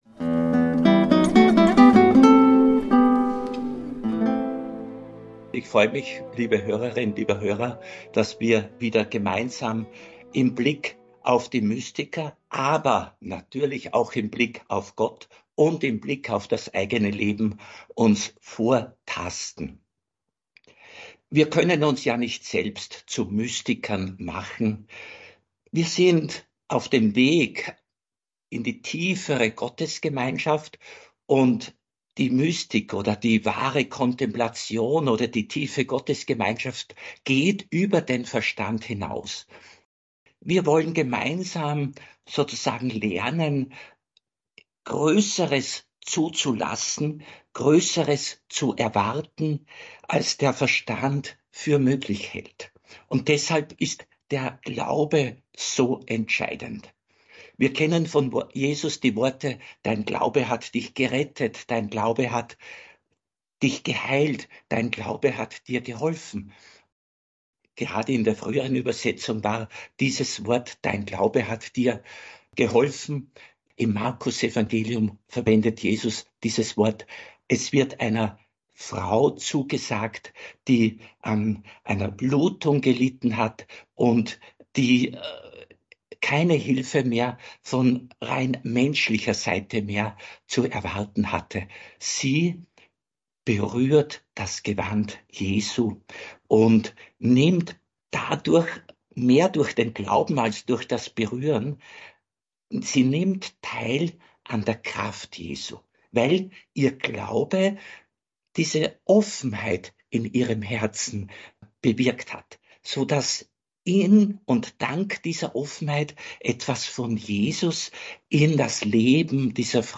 (Aufzeichnung der Radio Maria Sendung vom 13.11.2024) Mehr